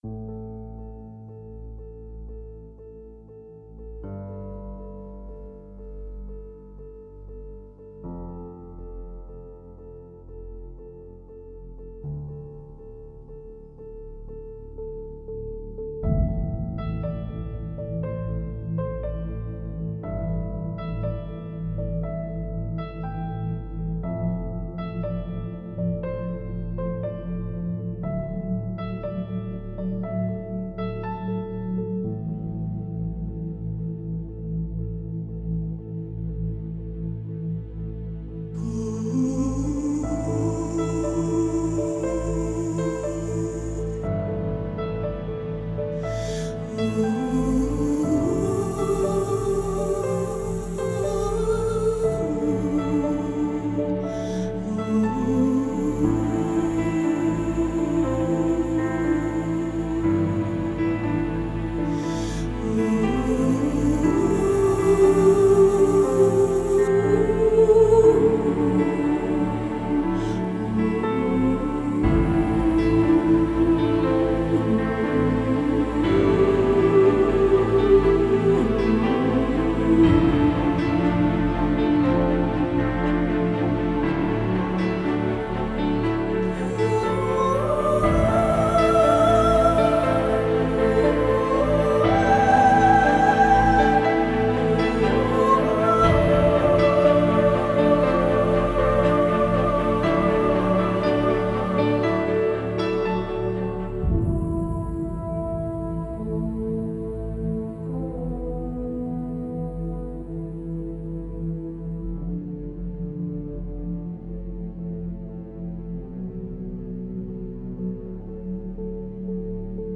10 آهنگ حماسی و ارکسترال 24